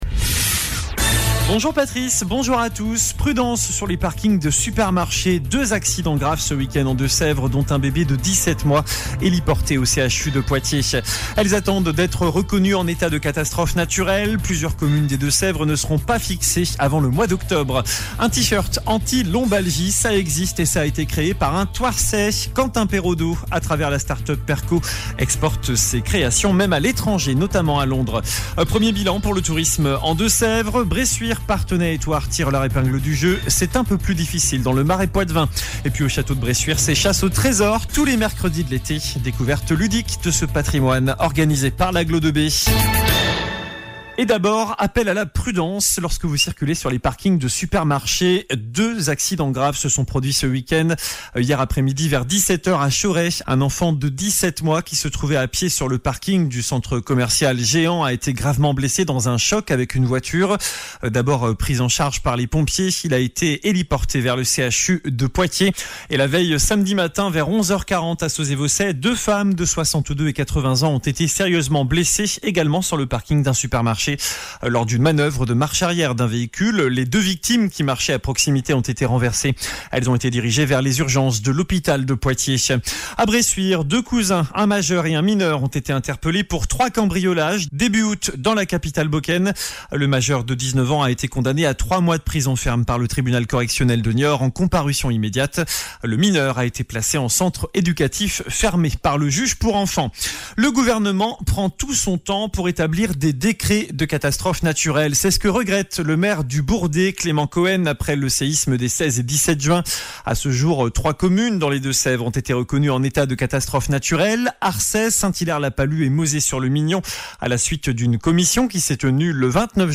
JOURNAL DU LUNDI 14 AOÛT ( MIDI )